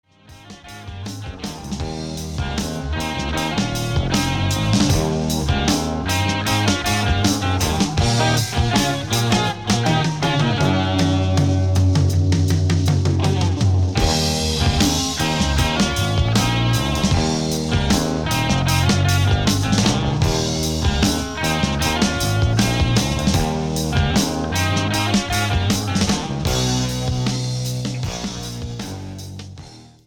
Recorded Location:Bell Sound Studios, New York City
Genre:Hard Rock, Heavy Metal